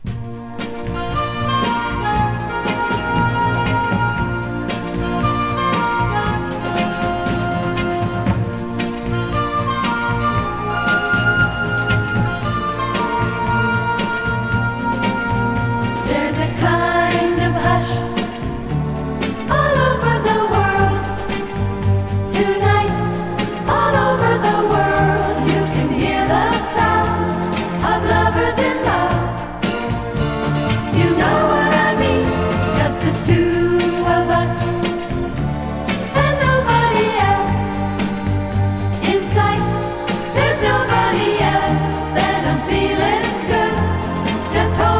12人の女性コーラスを配し各面のラスト以外はボーカルを前面に。
洒落た雰囲気に嬉しくなる素敵なソフト・ポップス集。"
Jazz, Pop, Vocal, Easy Listening　USA　12inchレコード　33rpm　Stereo